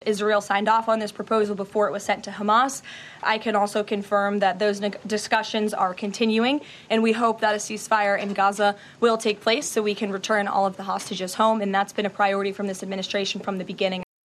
White House Press Secretary Karoline Leavitt says they’re waiting for a response from Hamas, who say they’ve received the plans and are studying them: